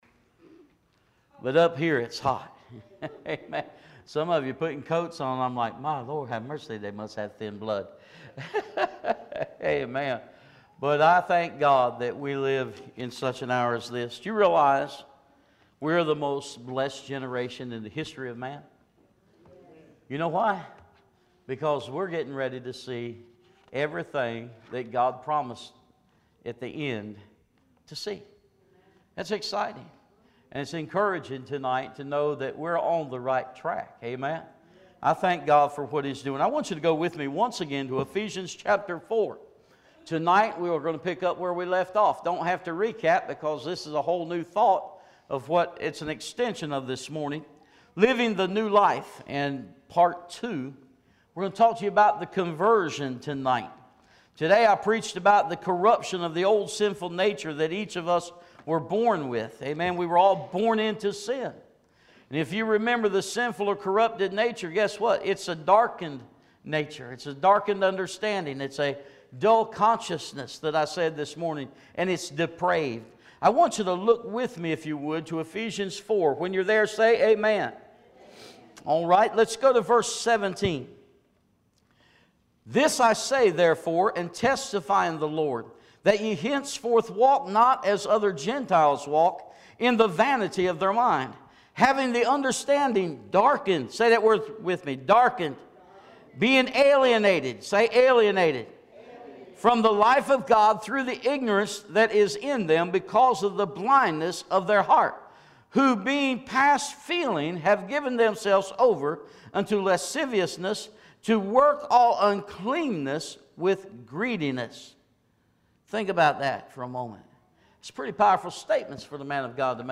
Living the New Life Service Type: Sunday Morning Worship Topics: Christian Living « Living the New Life